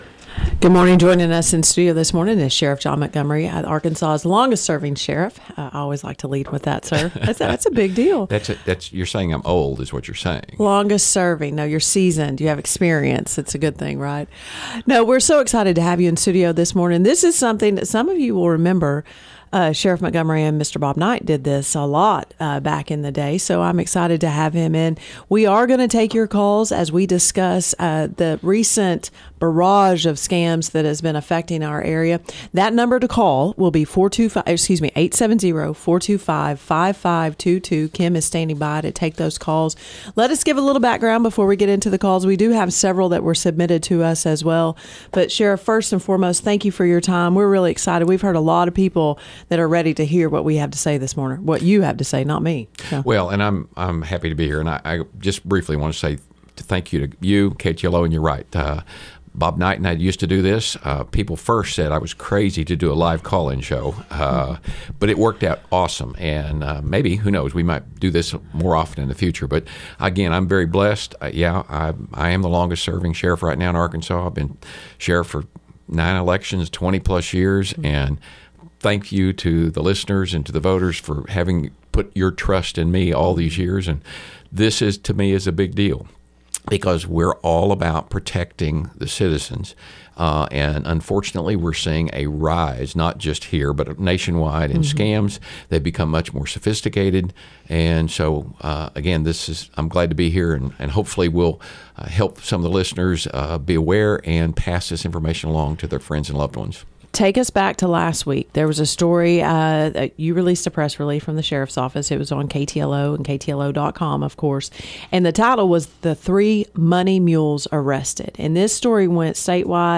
In response to a rise in scams targeting Baxter County residents, KTLO hosted Sheriff John Montgomery on Thursday morning for a live call-in program.Listeners were given the opportunity to call in with questions during the show or submit questions in advance by email.